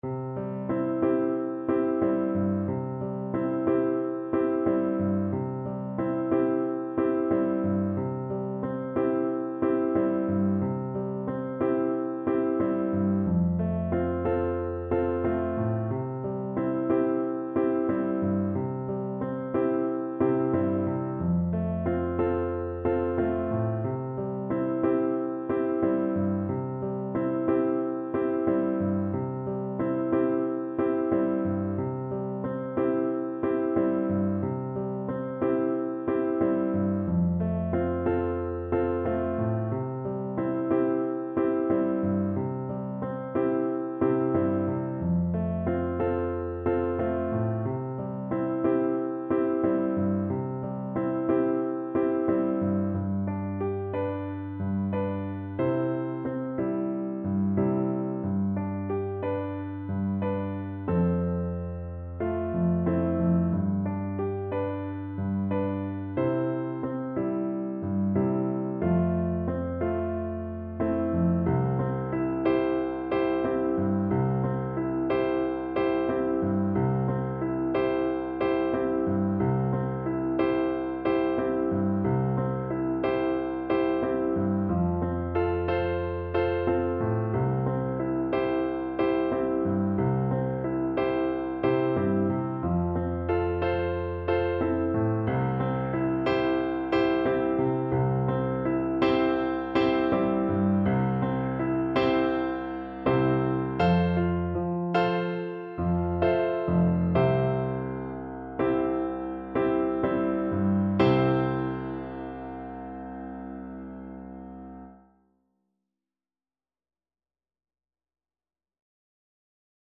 World Africa Burkina Faso Diarra Loro Lora
Flute
4/4 (View more 4/4 Music)
C major (Sounding Pitch) (View more C major Music for Flute )
Gently =c.126
Traditional (View more Traditional Flute Music)